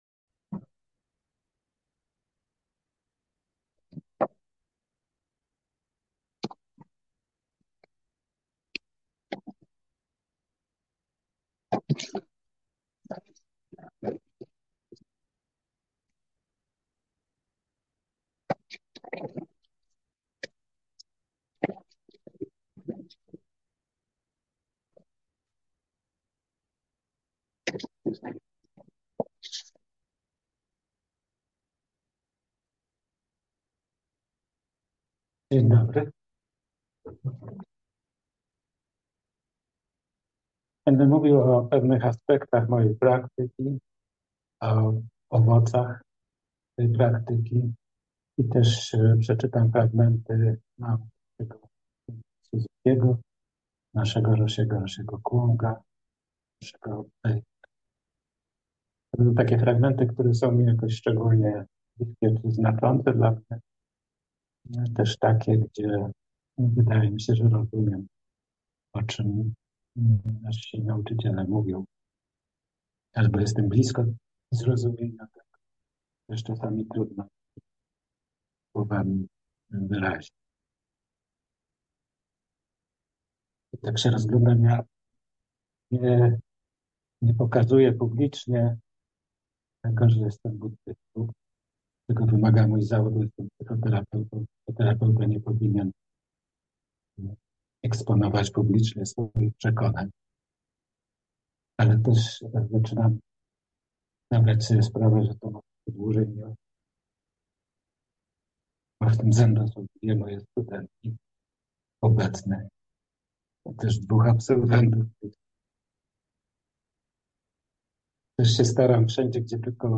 Wszystkie Mowy Dharmy